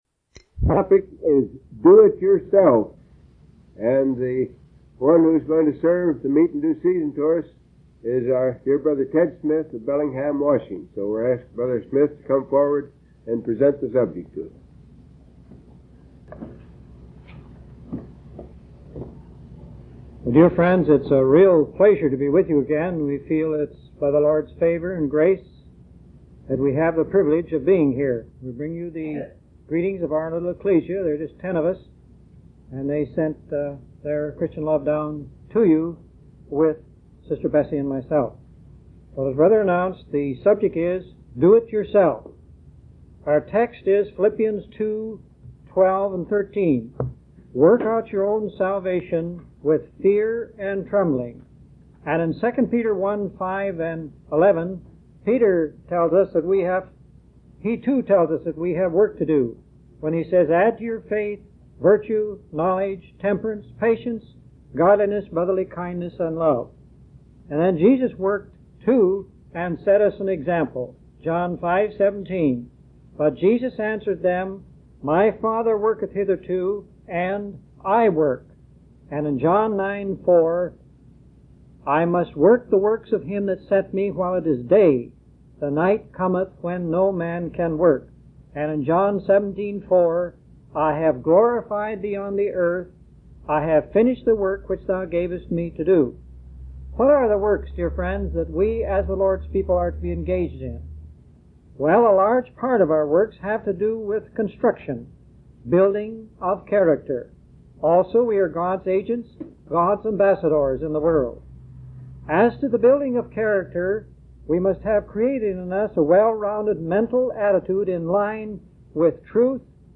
From Type: "Discourse"
Fort Worth, TX Covn March 15 1968